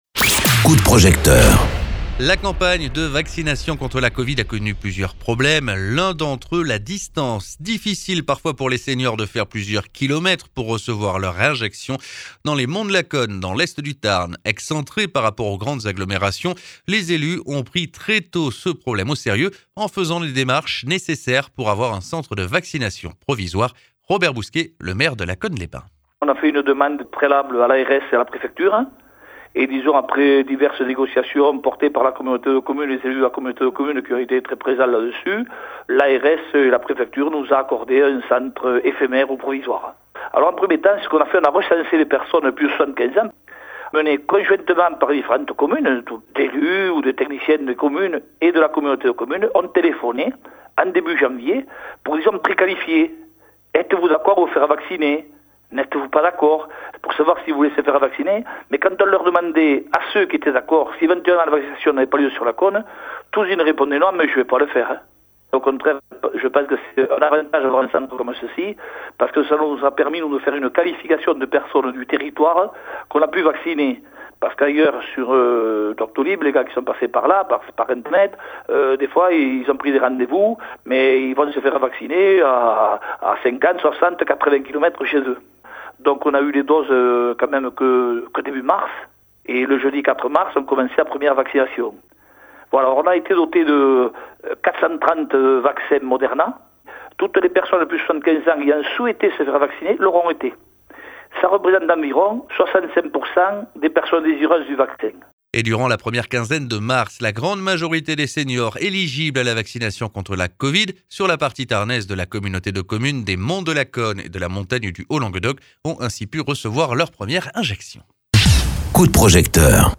Interviews
Invité(s) : Robert Bousquet, maire de Lacaune-les-bains (Tarn)